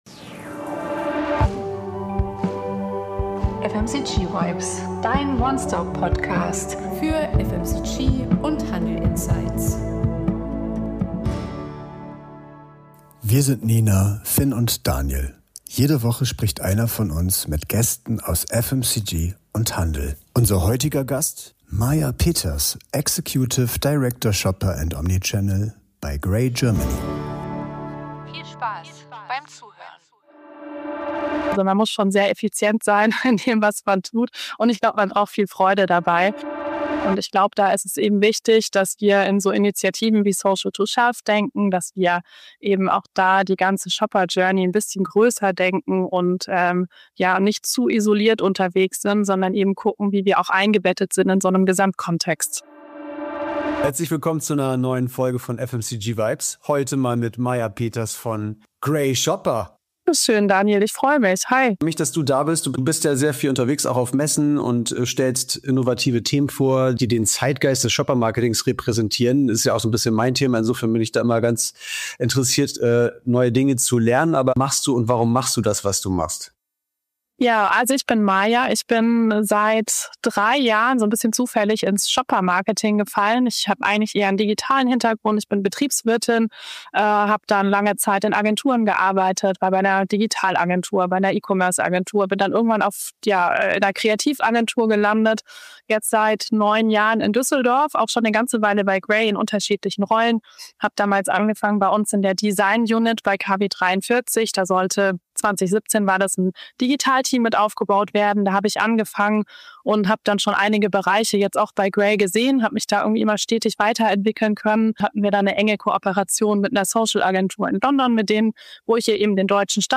Expertinnentalk